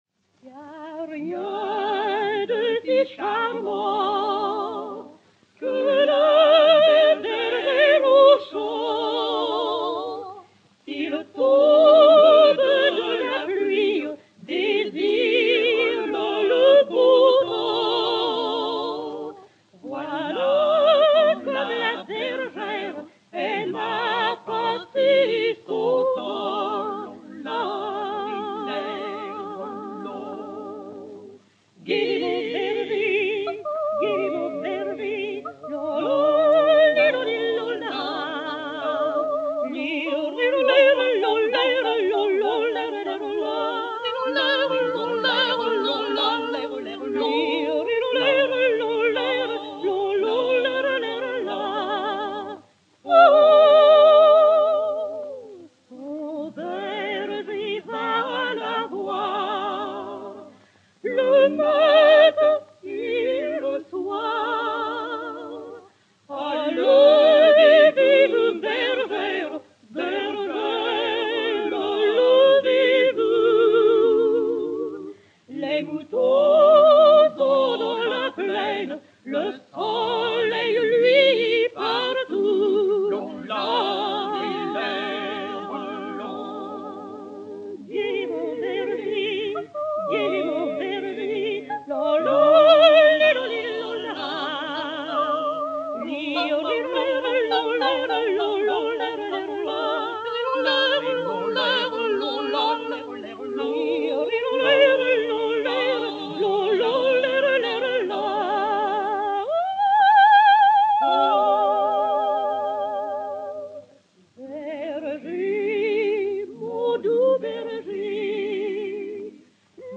soprano français
Chanson populaire, région de la Loire, harmonisée par Edmond Lavagne
Quatuor vocal féminin Seupel
YC 306, enr. à Paris en 1937/1938